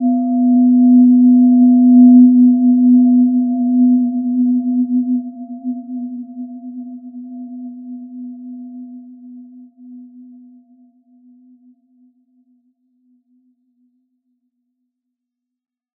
Gentle-Metallic-4-B3-p.wav